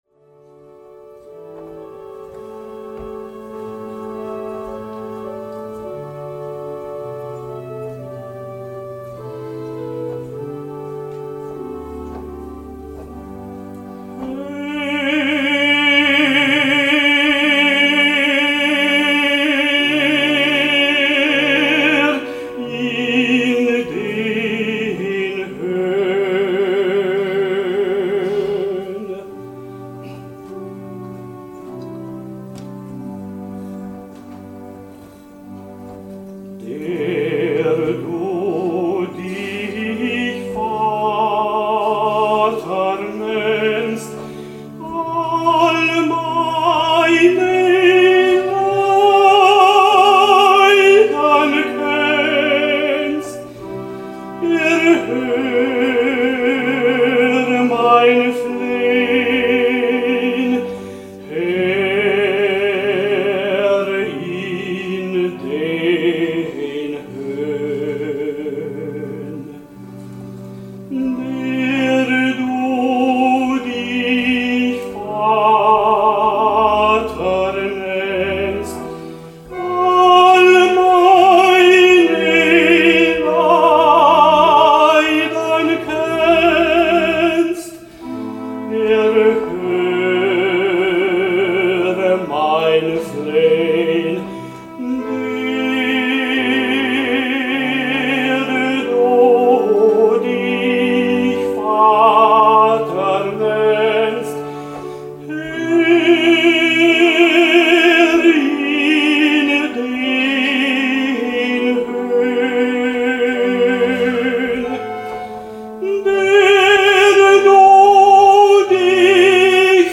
an der Orgel stimmlich begleitet